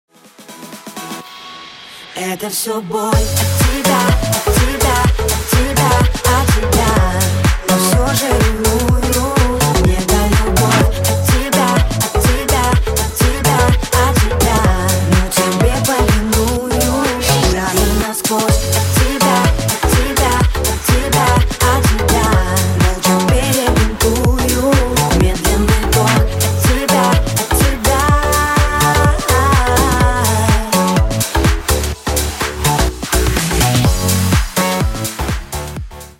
танцевальный рингтон Ура!